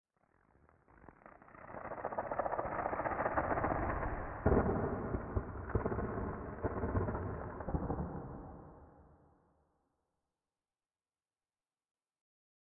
Minecraft Version Minecraft Version latest Latest Release | Latest Snapshot latest / assets / minecraft / sounds / ambient / nether / nether_wastes / addition5.ogg Compare With Compare With Latest Release | Latest Snapshot